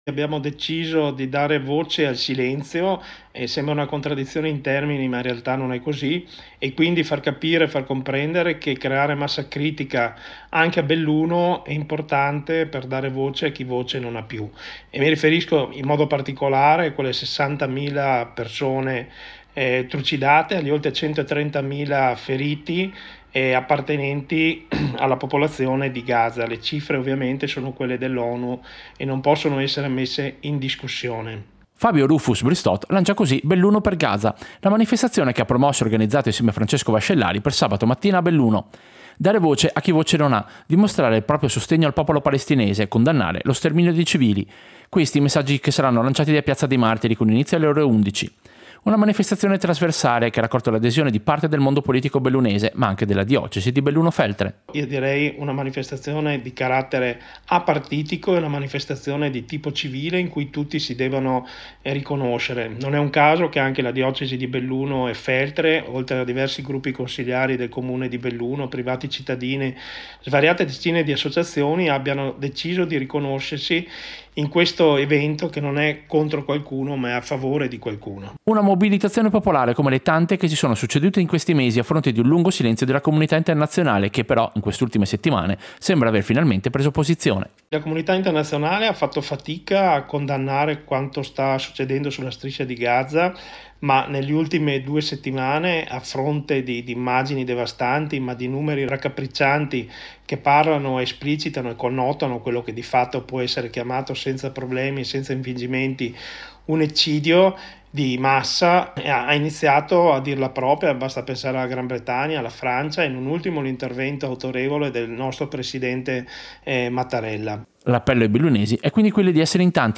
Servizio-Manifestazione-Belluno-per-Gaza-.mp3